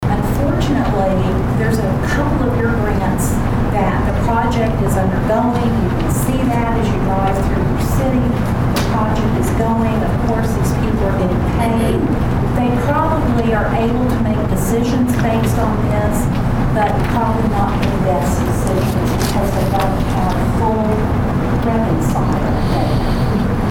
Auditor Gives Pawhuska Council an Update